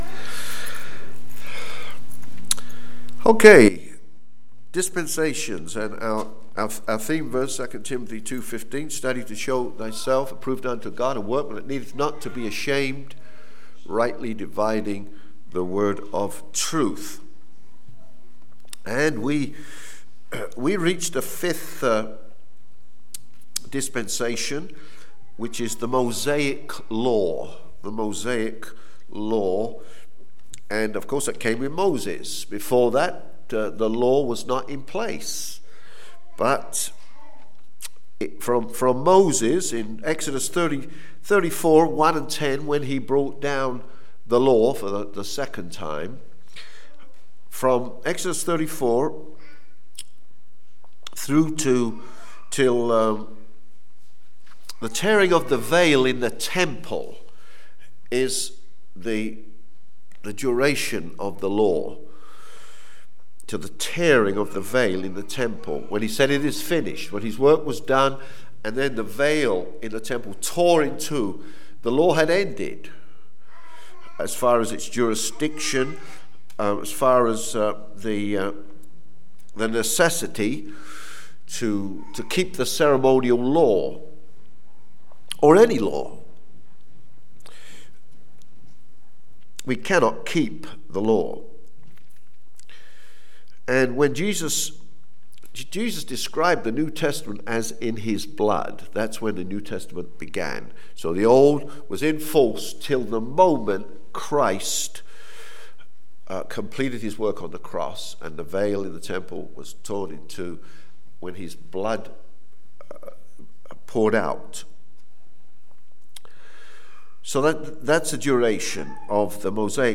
Berean Baptist Church